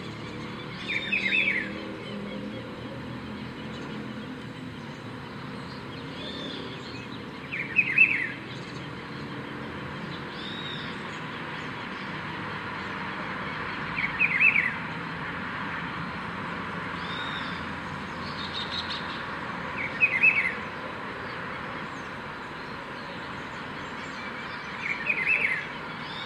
Rufous-browed Peppershrike (Cyclarhis gujanensis)
Class: Aves
Province / Department: Tucumán
Condition: Wild
Certainty: Recorded vocal